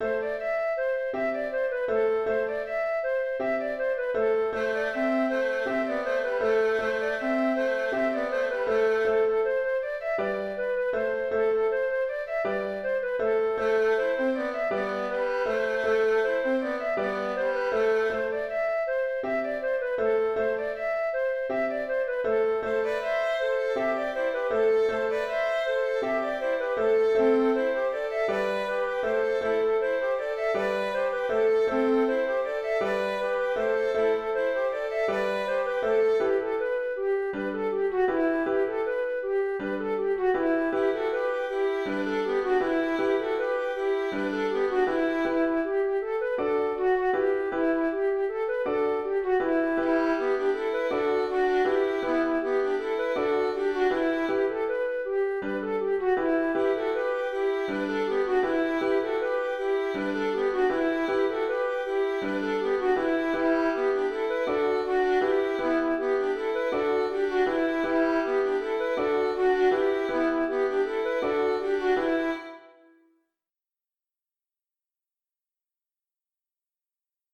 Quand les garçons vont aux noces (Ridée 6 temps) - Musique bretonne
Dans la tonalité de La mineur, il y a deux versions un peu différentes, à choisir ou à alterner au choix des musiciens. Auteur : Trad. Bretagne.